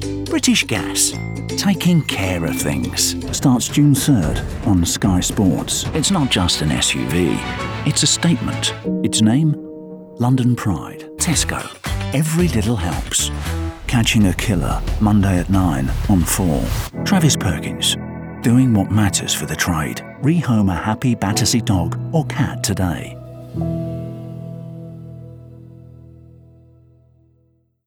20 Second SoundBite
Male
Estuary English
Gravelly
Warm